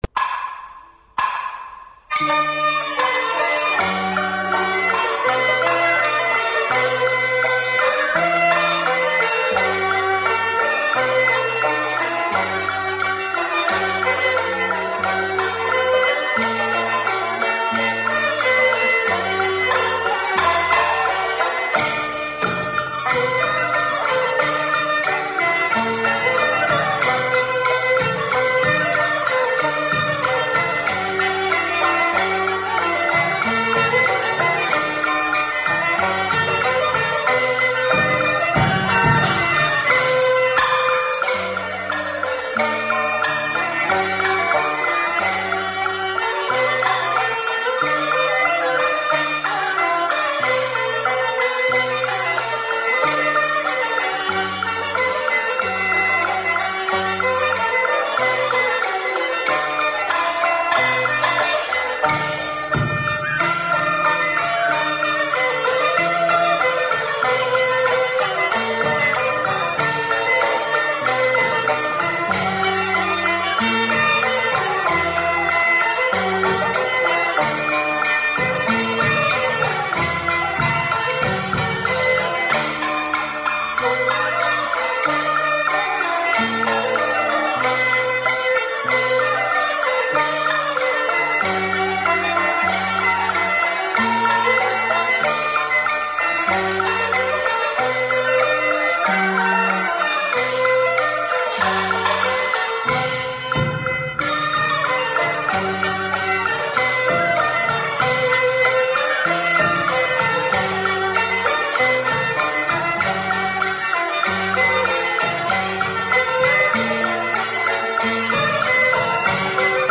上海道教音乐是一个各乐种成分相互融汇、并具有江南独特音乐风格和丰富道教色彩的音乐。它伴随着上海道教浩繁的斋醮科仪的进行，灵活巧妙地在各种场合穿插运用，有时鼓声震天，气势磅礴，以示召神遣将、镇邪驱魔；有时丝竹雅奏，余音绕梁，使人身心清静，如入缥缈之境。